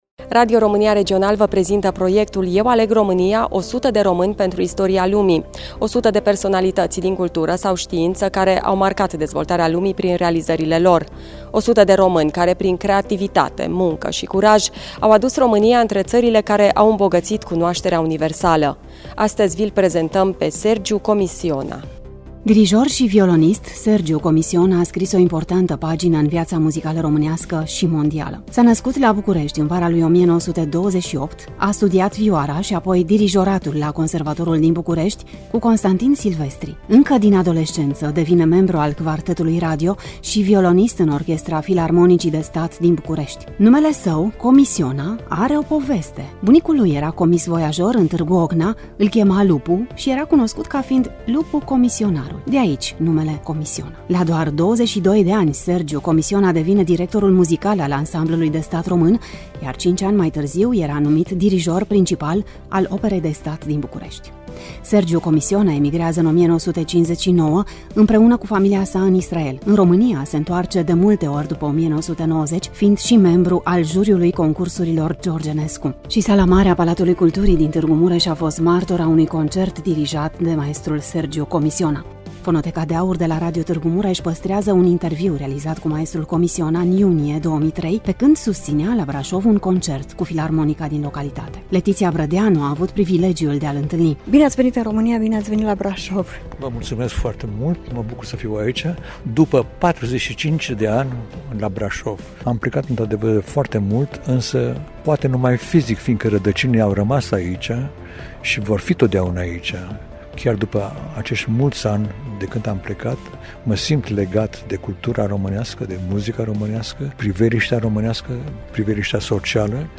Fonoteca de aur de la Radio Tg.Mureş păstrează un interviu realizat cu maestrul Comissiona în iunie 2003, pe când susţinea la Braşov un concert cu Filarmonica din localitate.
Studioul: Radio România Tg.Mureş
Voice over